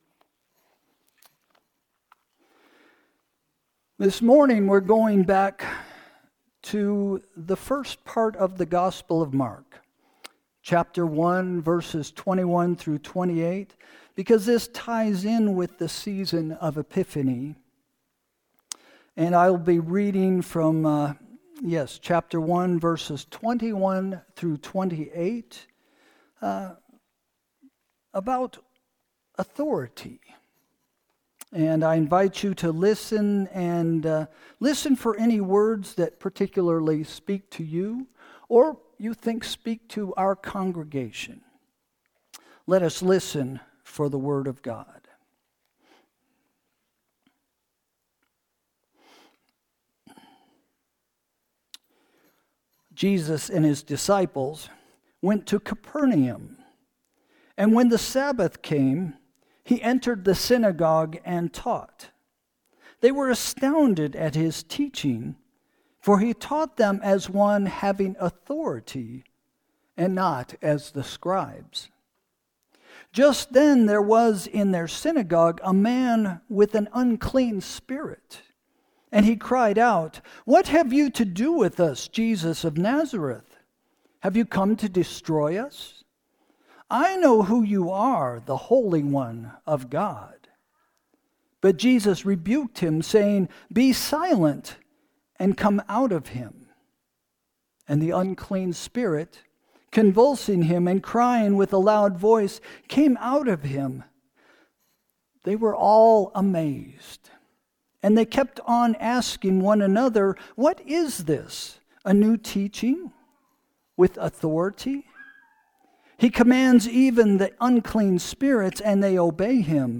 Sermon – January 11, 2026 – First Christian Church